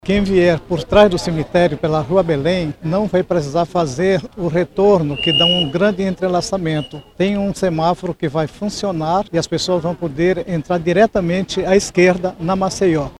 O diretor-presidente do Instituto Municipal de Mobilidade Urbana (IMMU), Arnaldo Flores, explicou que as mudanças na avenida Maceió com a rua Belém vão facilitar a circulação de veículos e pedestres na região.